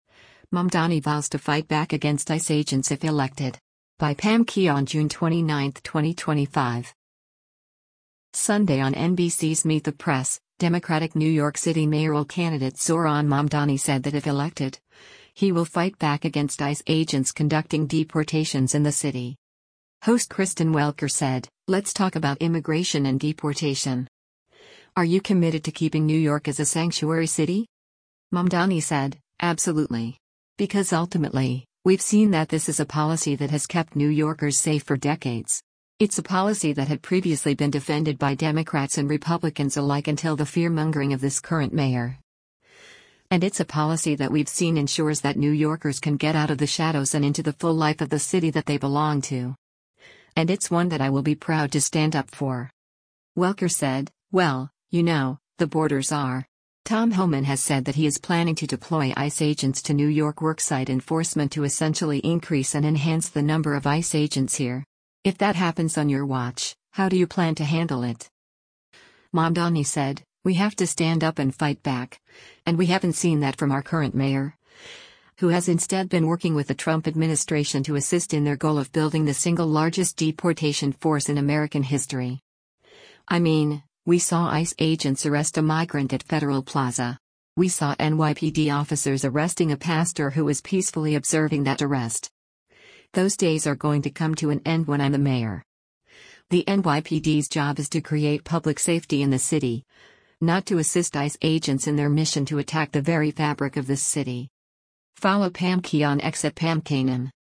Sunday on NBC’s “Meet the Press,” Democratic New York City mayoral candidate Zohran Mamdani said that if elected, he will “fight back” against ICE agents conducting deportations in the city.